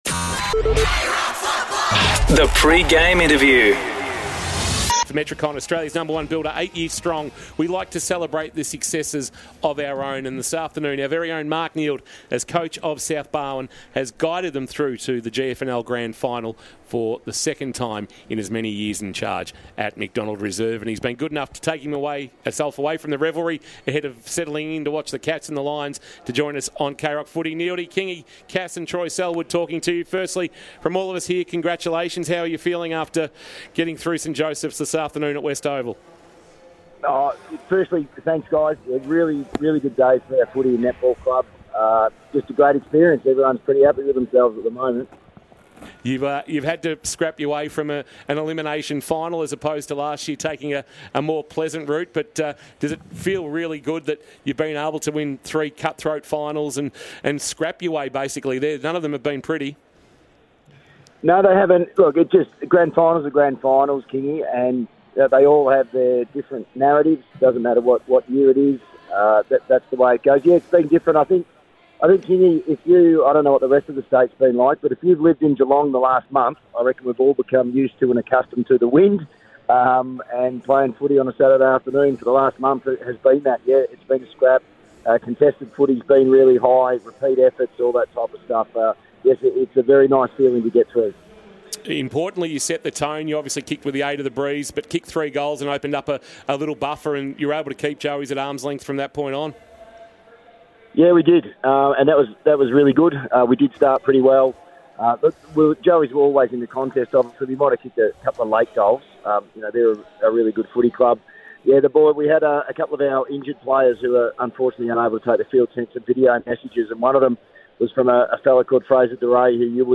2024 - AFL - Preliminary Final - Geelong vs. Brisbane: Pre-match interview - Mark Neeld (South Barwon Coach)